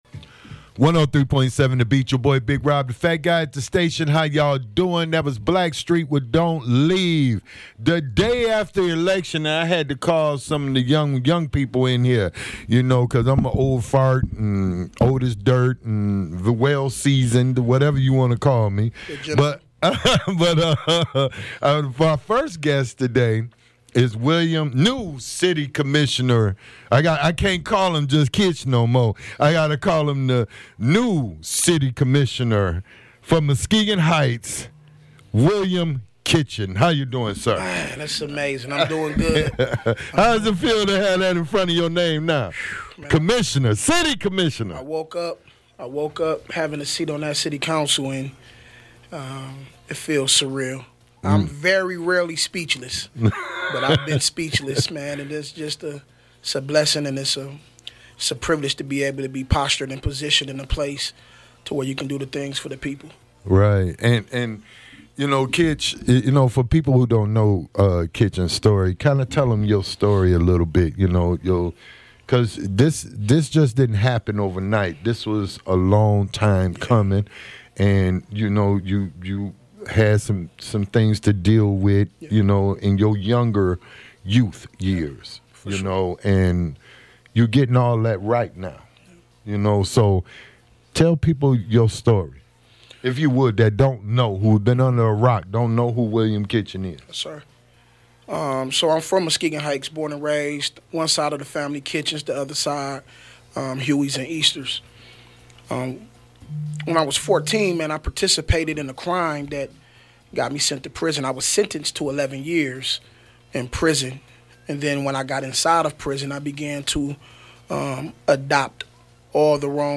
Interview with Muskegon Heights City Council Member William Kitchen